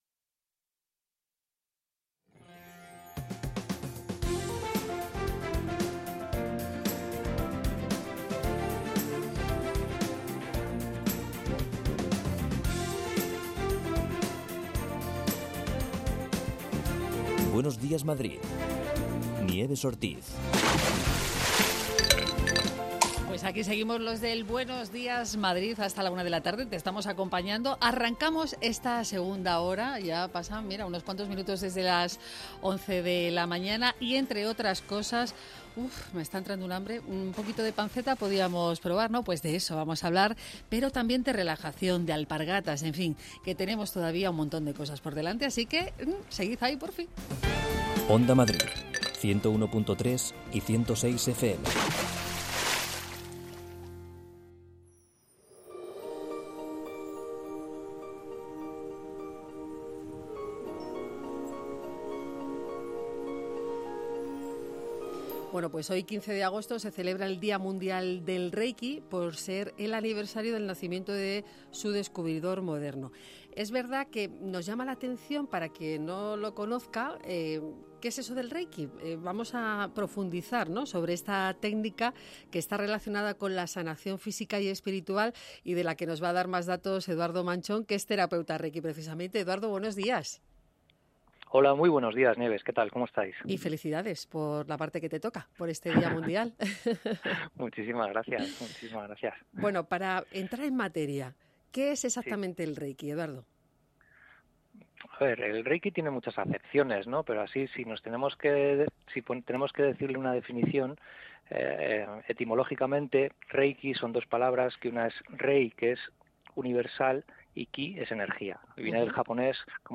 Magacín matinal que busca informar desde las 6 de la mañana a los madrileños analizando lo que ocurre en la actualidad con conexiones en directo desde los puntos calientes de la actualidad, reportajes y entrevistas.&nbsp